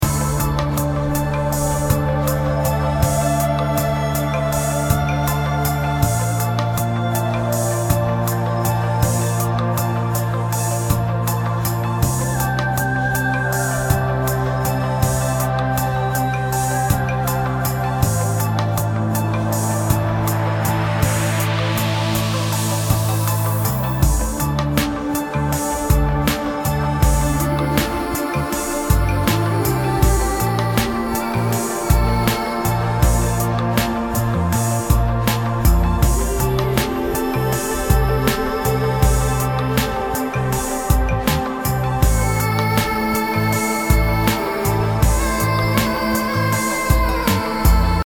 528hz BPM80-89 Game Instrument Soundtrack インストルメント
孤独がそっと寄り添ってくる
BPM 80